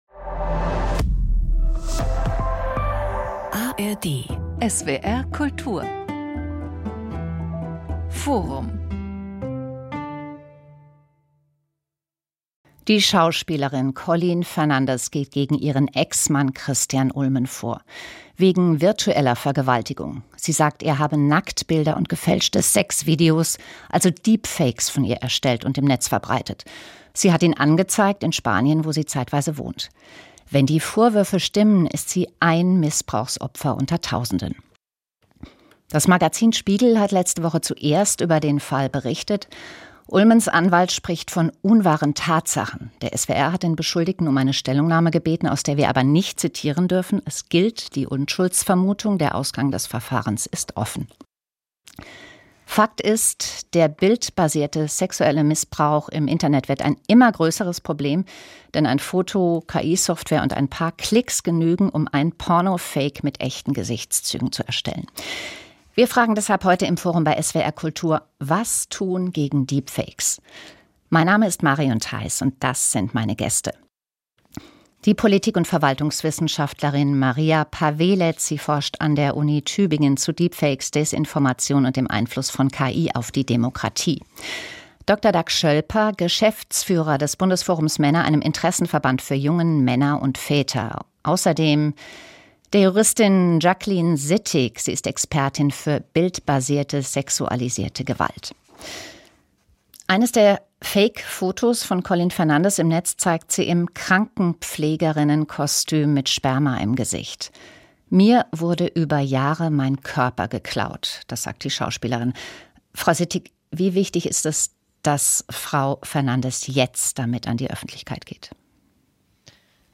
SWR Kultur Forum Der Fall Fernandes - Was tun gegen Deepfakes?